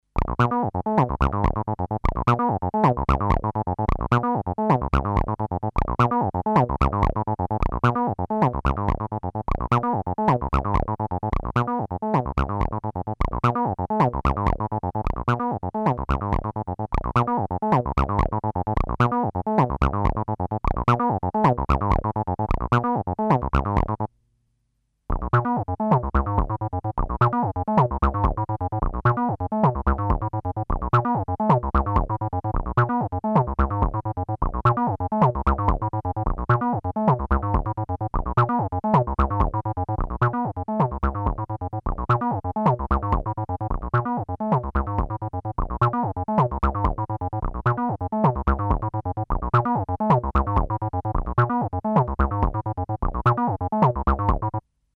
Both the TB303 and TM3030 are being sequenced via MIDI from a Sequentix P3 sequencer.
The P3 plays the pattern alternating a bar at a time between the two instruments.
Initially, both instruments are panned to centre, but after a few bars, one is panned to the left and the other right, to show that it isn't just one instrument playing the same pattern.
Note that both the TB303 and TM3030 used have some mods from the stock TB303 sound.